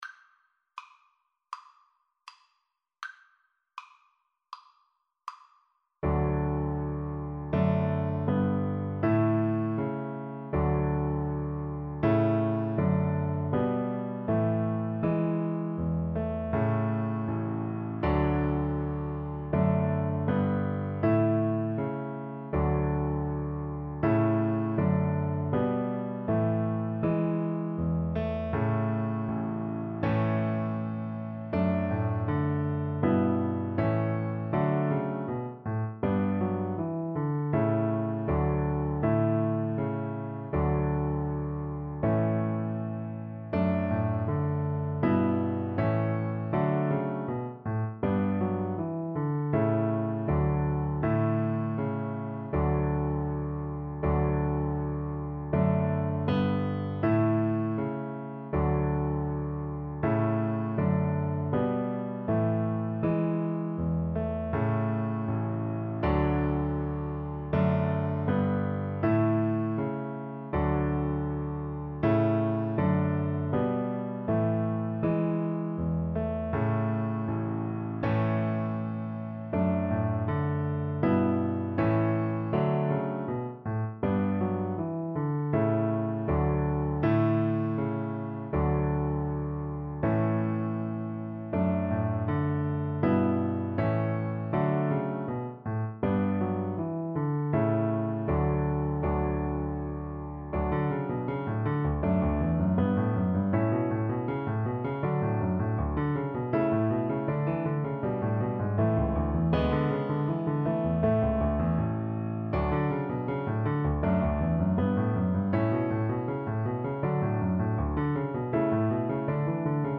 Allegretto =80
4/4 (View more 4/4 Music)